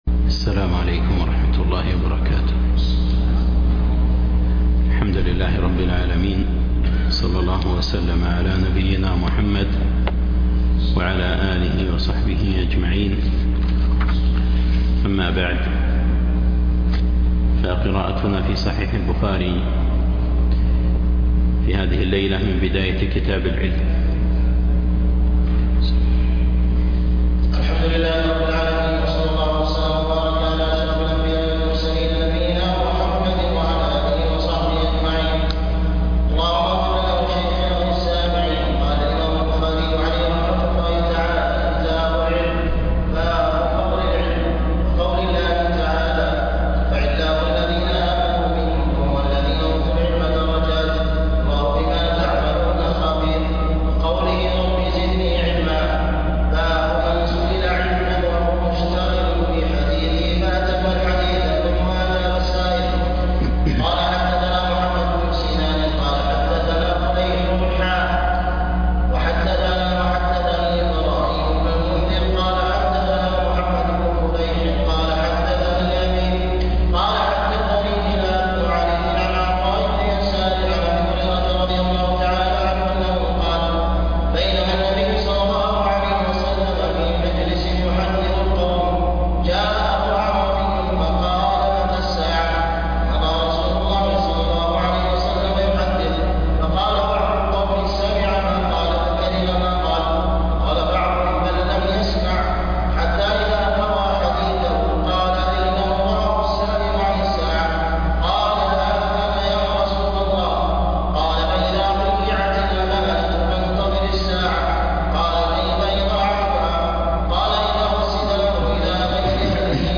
الدرس 13